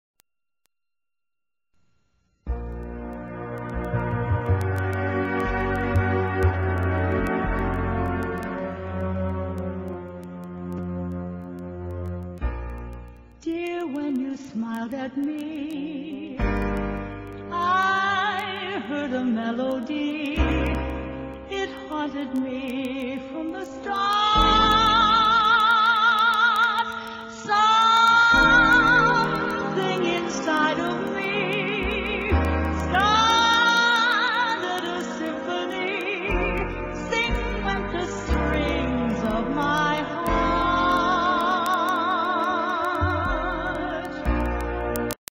w/vocal